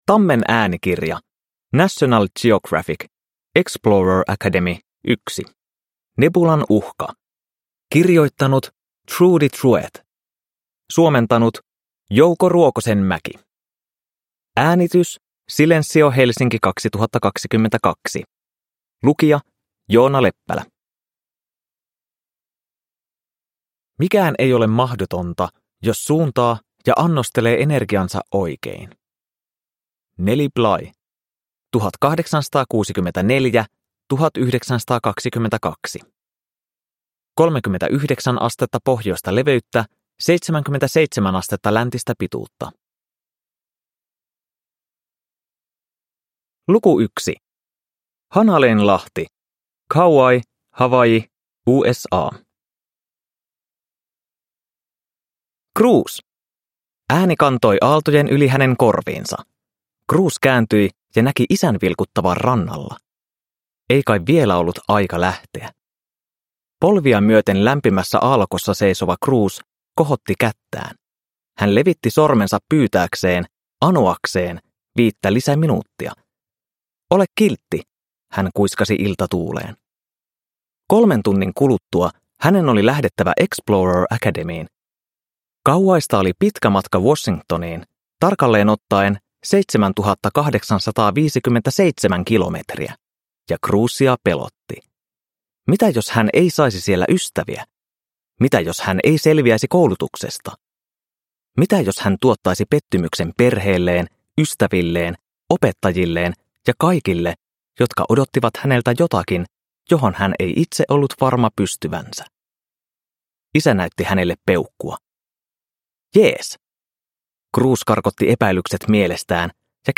Explorer Academy 1. Nebulan uhka – Ljudbok